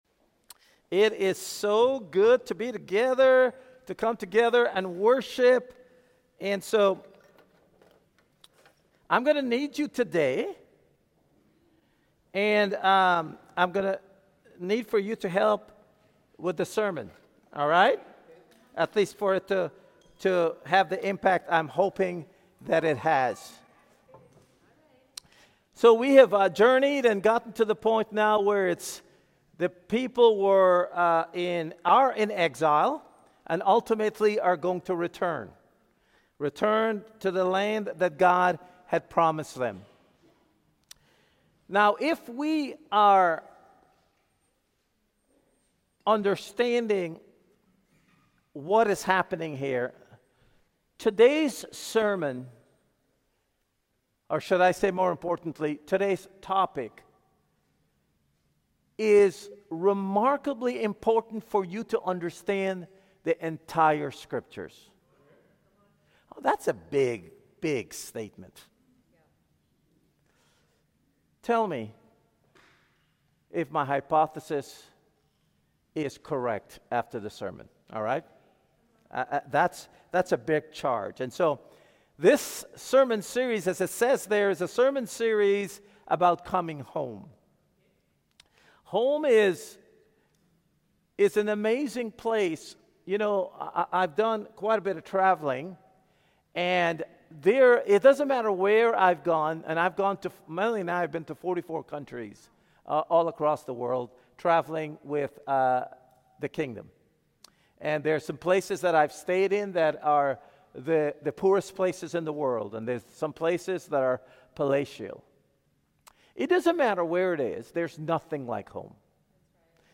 Ottawa Church Of Christ Sermon Podcastleri